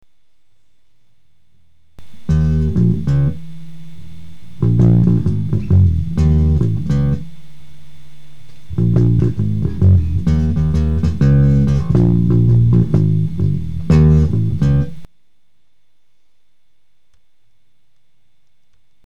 A  Fender p style  nitro cellulose 2 colour sunburst finish. EMG pickup and covers added